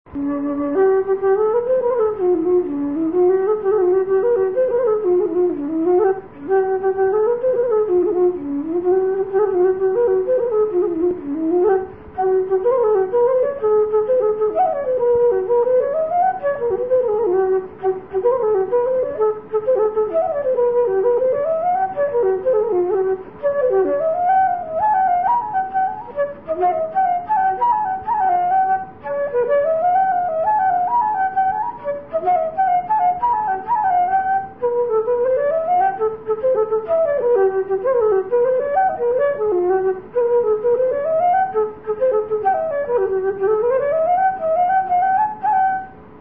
Here are a few short folk melodies I have composed.
A slip jig (of course!) which goes every which way, but somehow manages to land on its feet in the end.